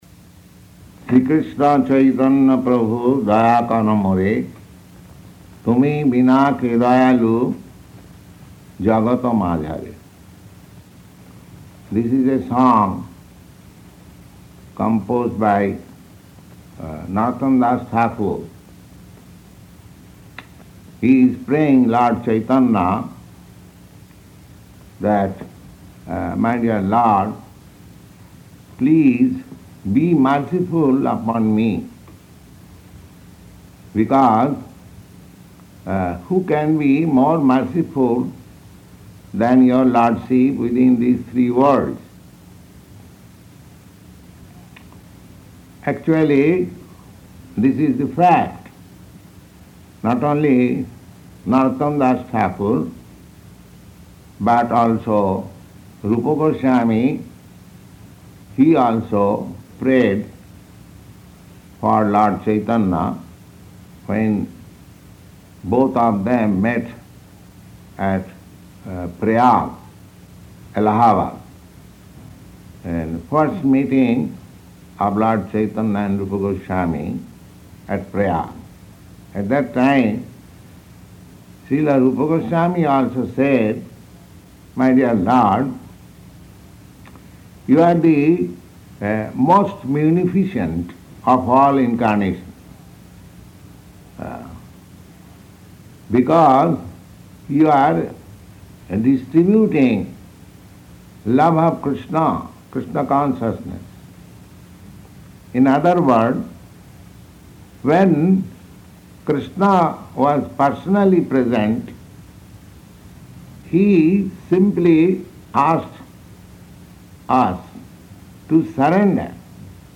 Type: Purport
Location: Los Angeles